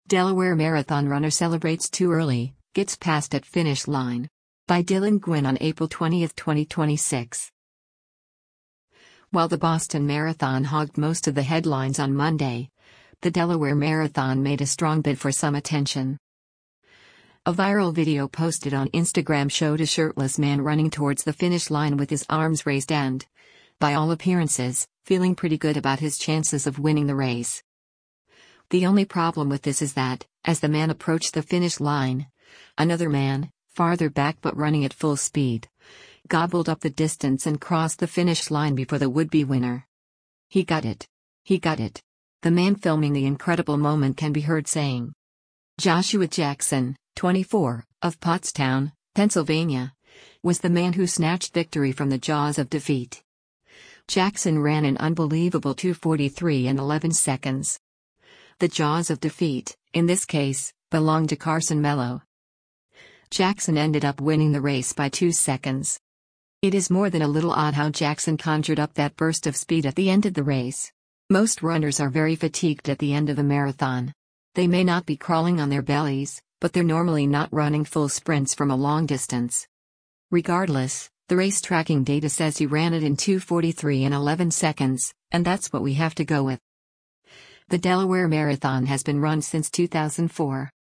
“He got it! He got it!” the man filming the incredible moment can be heard saying.